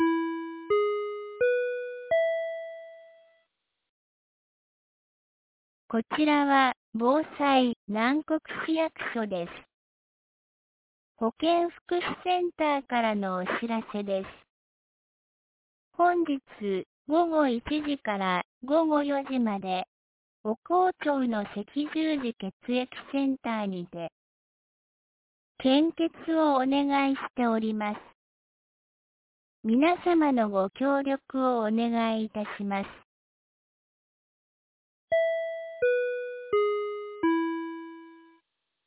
2025年07月04日 10時00分に、南国市より放送がありました。